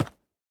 1.21.4 / assets / minecraft / sounds / mob / goat / step6.ogg